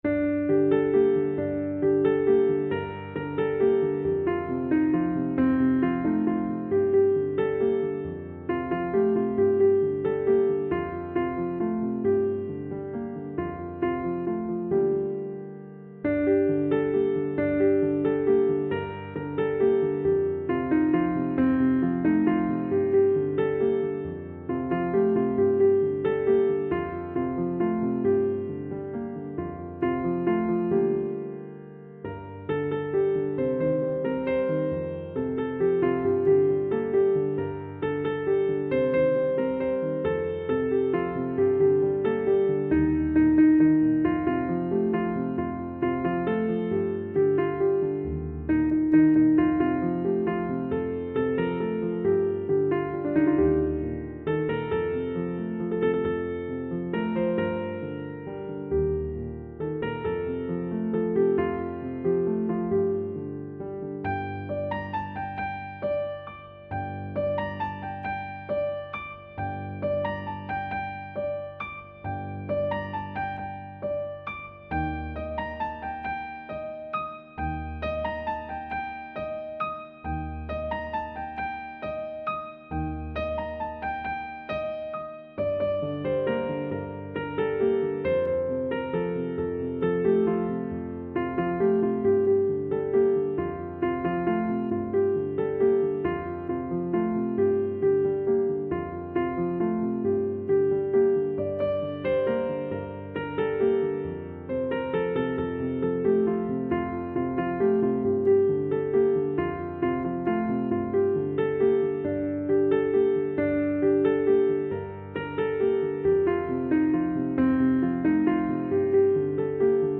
تنظیم شده برای پیانو